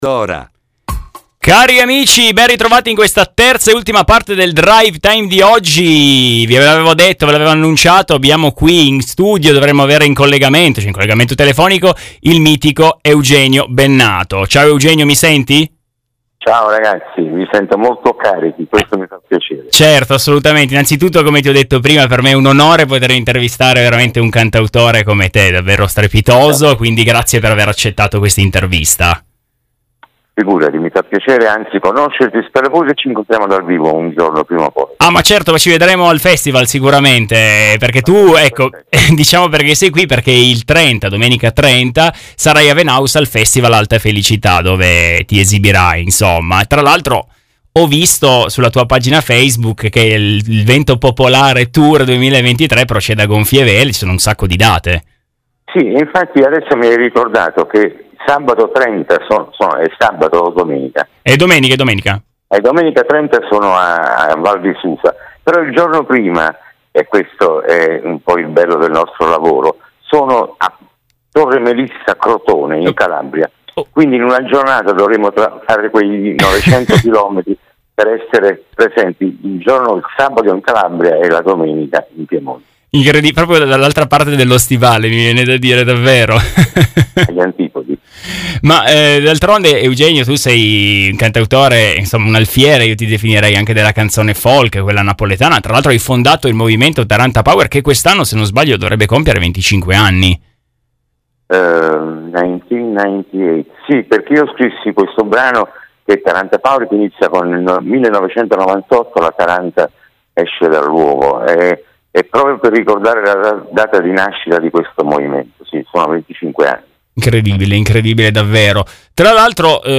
Festival Alta Felicità 2023 - Intervista con Eugenio Bennato - Radio Dora & Radio Frejus
Oggi pomeriggio abbiamo avuto l'immenso piacere di ospitare ai nostri microfoni il grandissimo cantautore Eugenio Bennato.
Intervista-con-Eugenio-Bennato.mp3